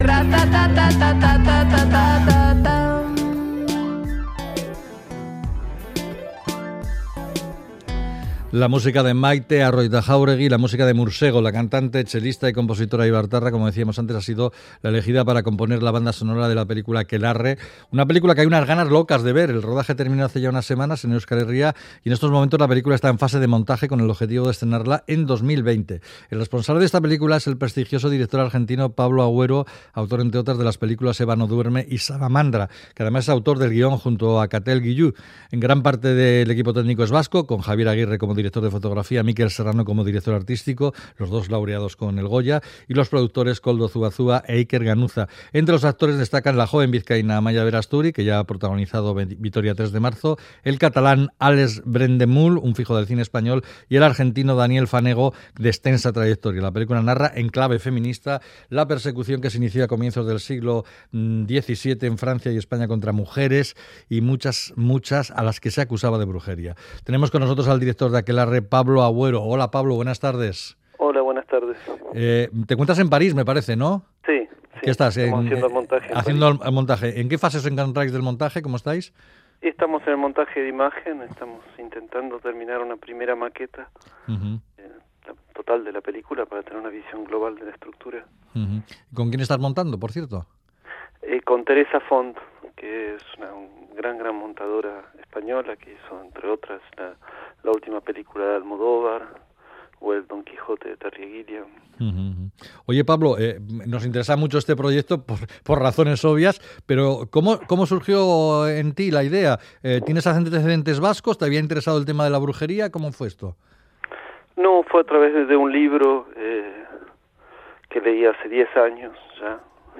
Audio: Hablamos con el director de cine argentino Pablo Agüero sobre Akelarre